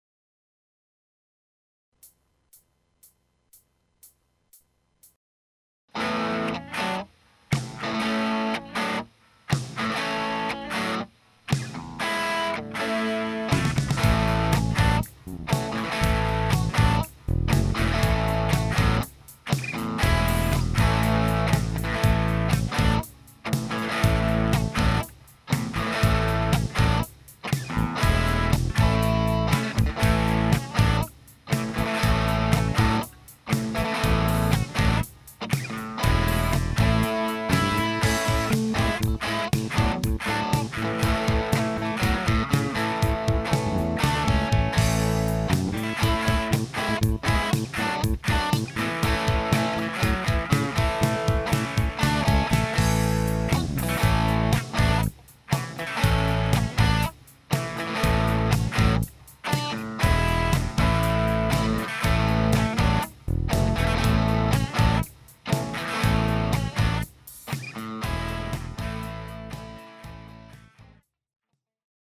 Badly double tracked.
Prs guitar. Bit of chorus. lots of treble booster.